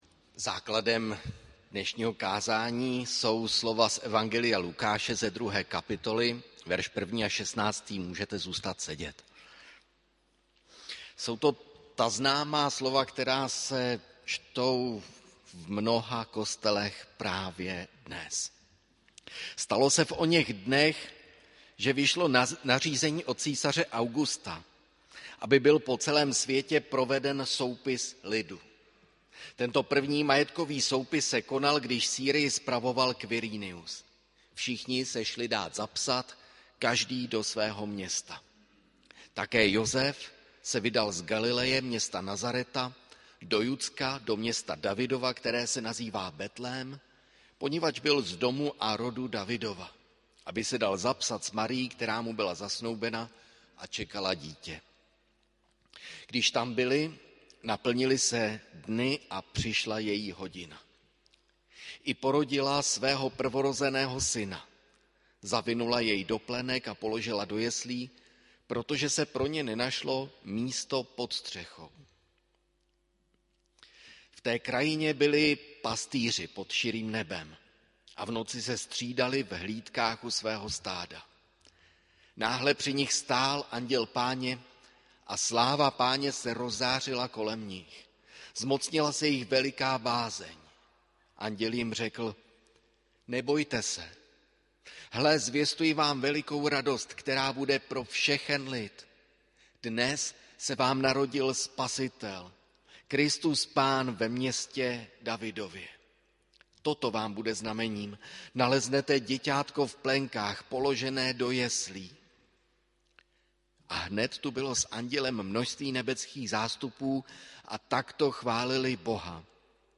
Půlnoční bohoslužba 24. prosince 2021 AD
pulnocni_kaz.mp3